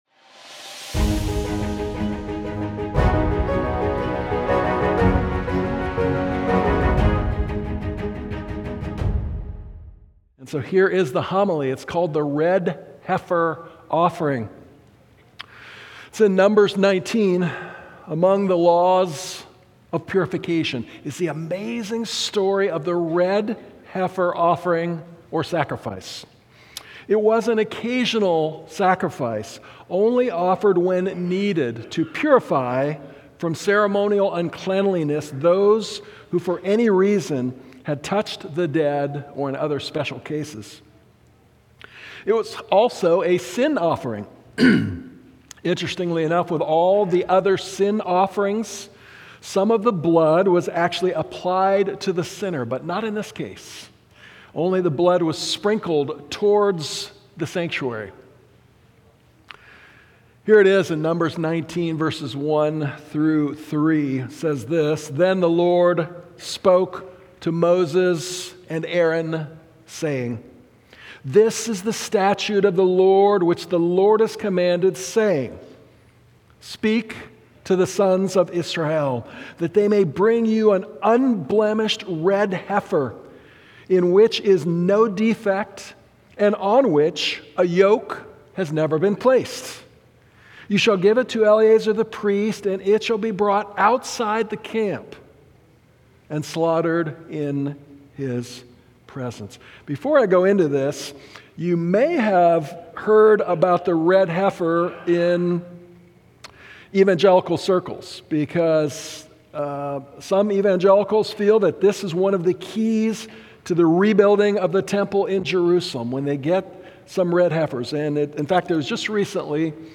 A message from the series "Central Sermons."